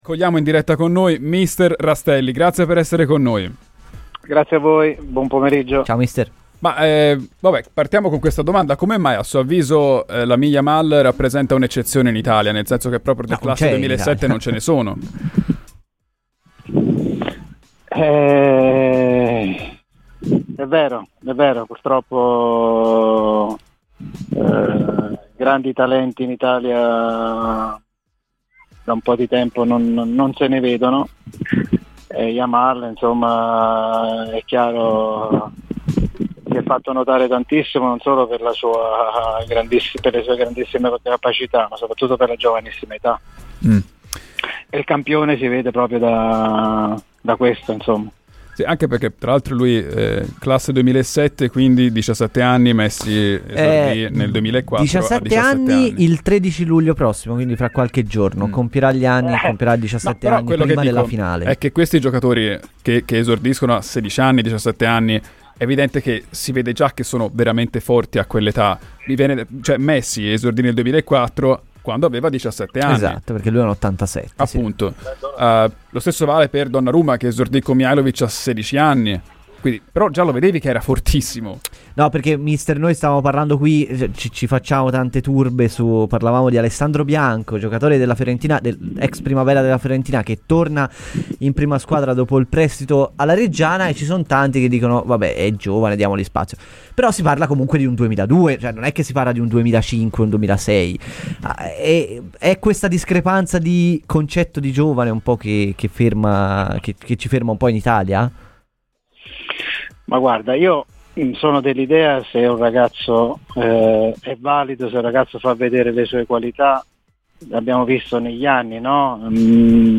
Ospite ai microfoni di Radio FirenzeViola, durante “Palla al Centro”, il tecnico Massimo Rastelli ha parlato dei vari temi legati alla Fiorentina, iniziando da una riflessione su Valentini: "L'ho visto giocare poco ma se la Fiorentina vuole portarlo a Firenze lo prenderei fin da subito anziché aspettare che arrivi a zero".